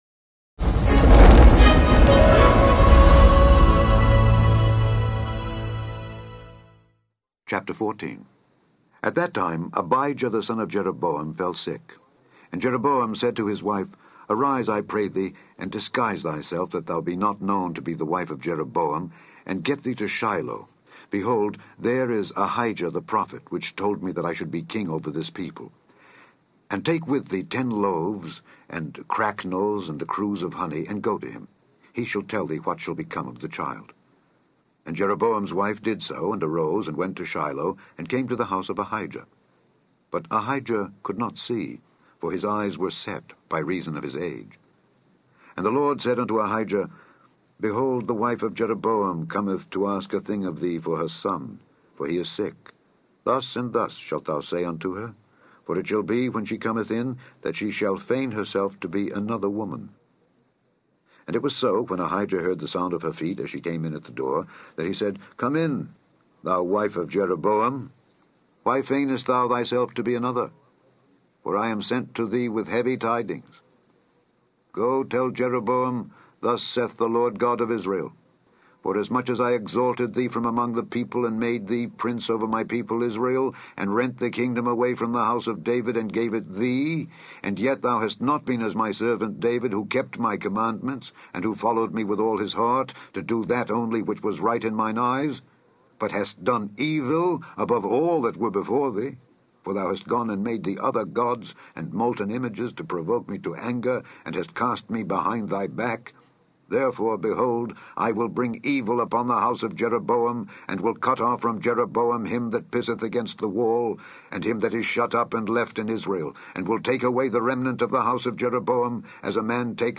In this podcast, you can listen to Alexander Scourby read 1 Kings 14-18 to you.